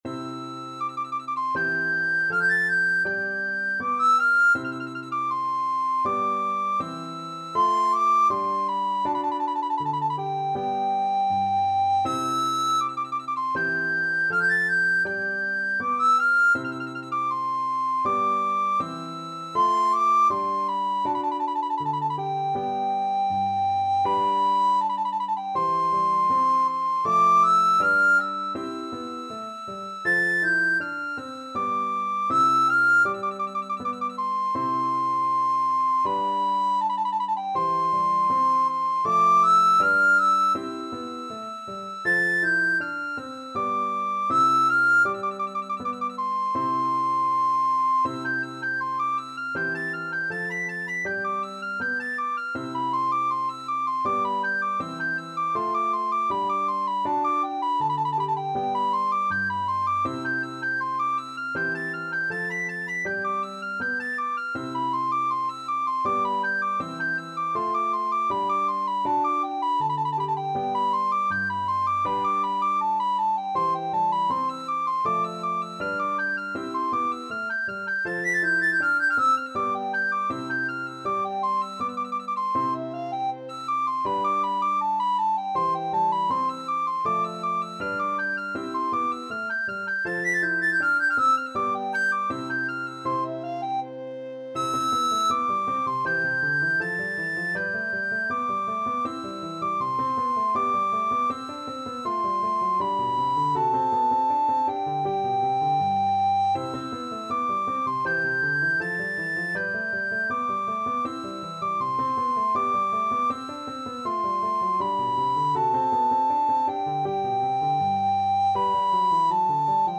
4/4 (View more 4/4 Music)
Allegretto =80
Classical (View more Classical Recorder Music)